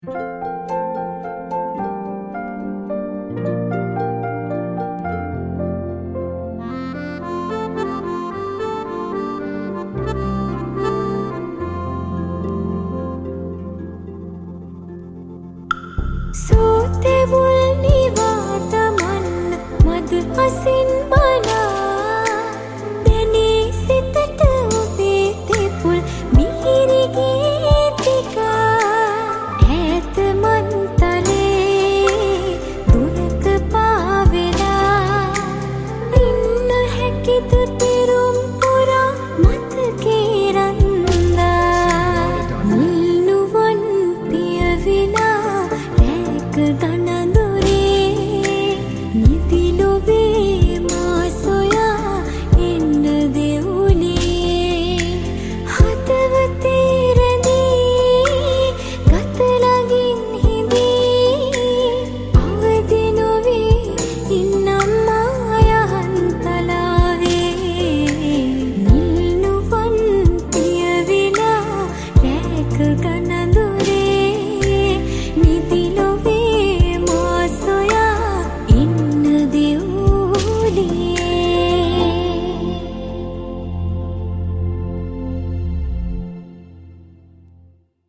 Music Re-Arranged, Mix and Mastering